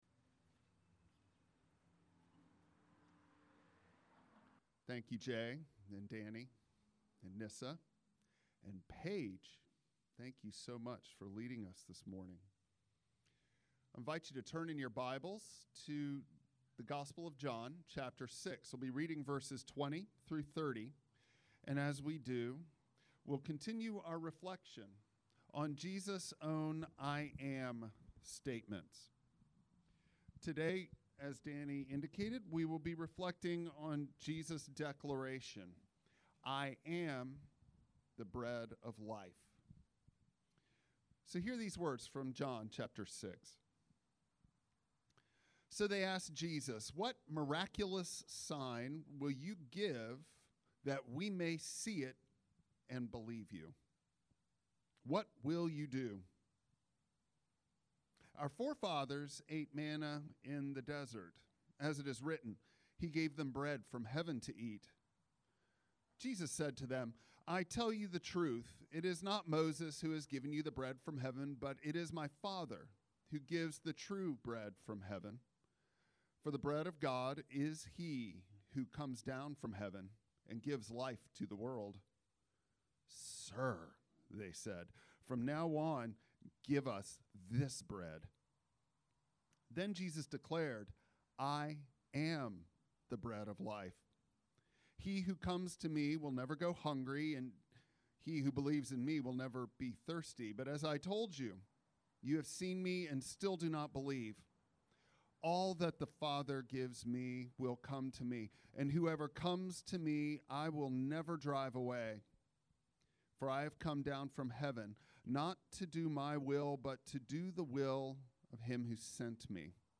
Passage: John 6:30-40 Service Type: Traditional Service